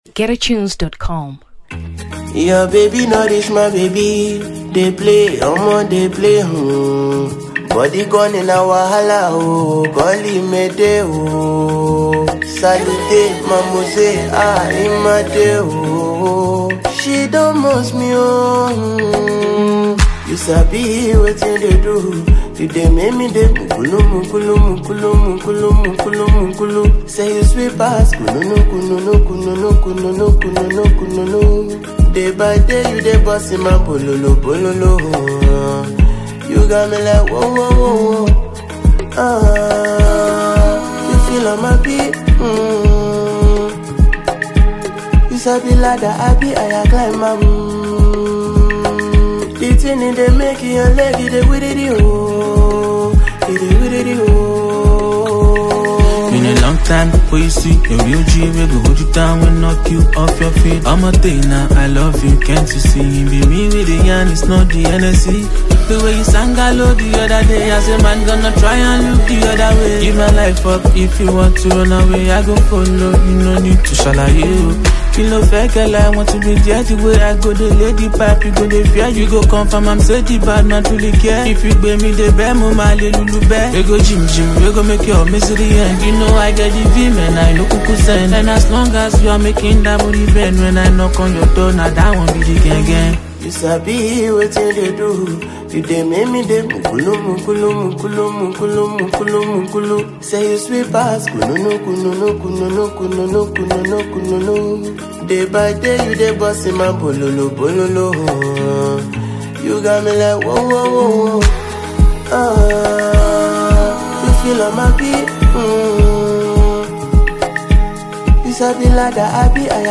Afrobeats 2023 Nigeria